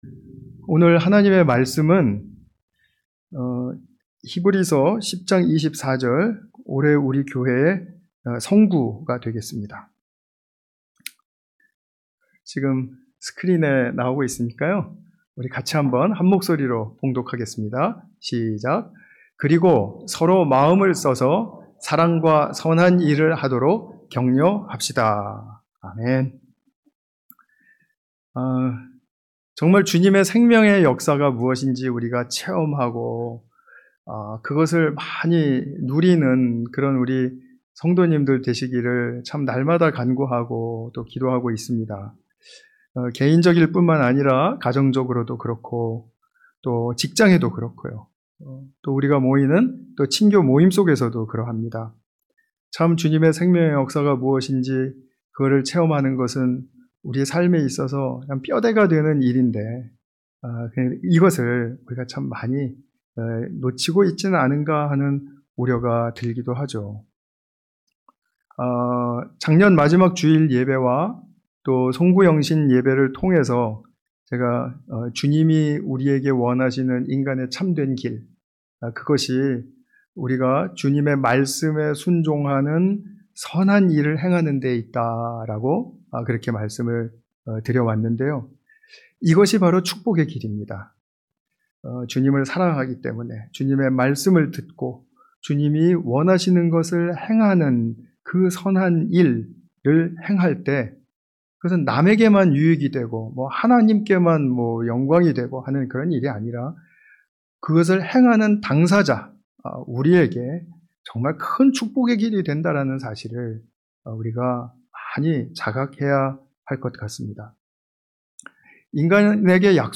신년감사예배 – 사랑과 선한 일을 격려합시다